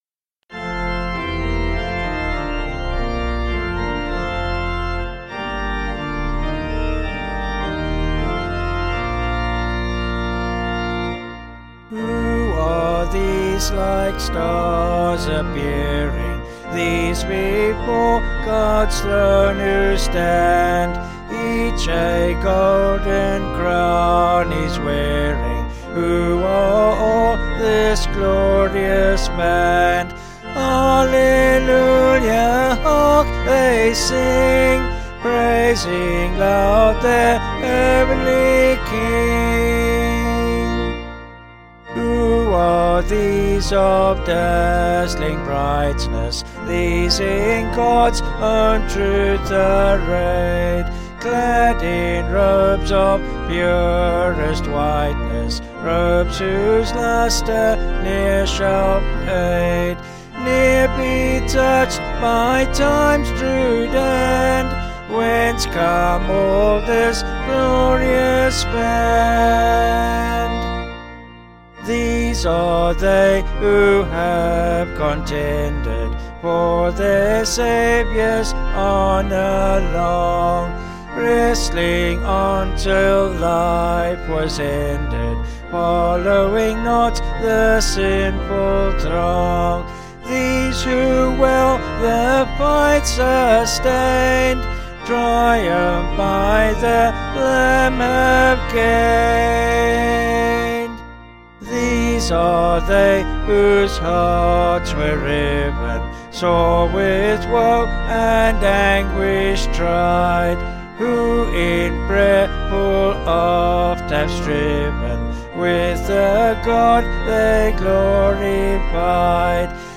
Vocals and Organ   705.9kb Sung Lyrics